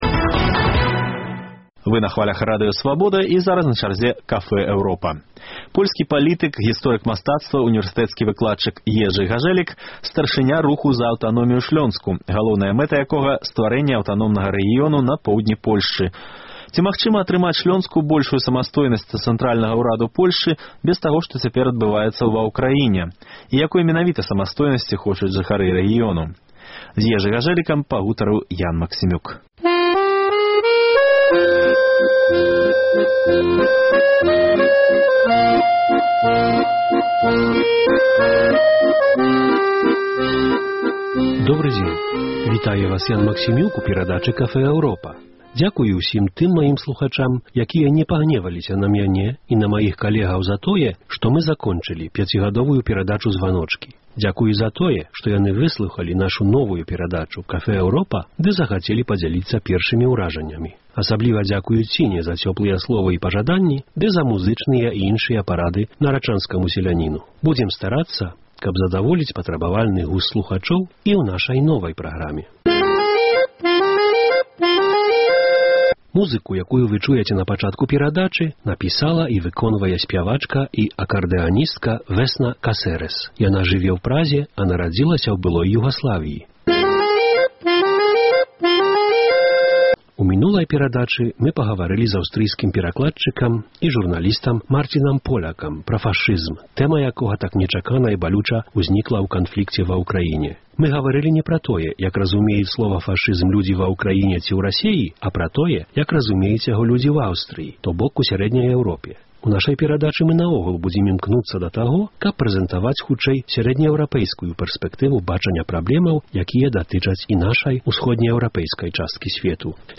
Шлёнская аўтаномія – уцёкі ад Эўропы ці набліжэньне да яе? Госьць Café Europa – польскі палітык, гісторык мастацтва, унівэрсытэцкі выкладчык Ежы Гажэлік.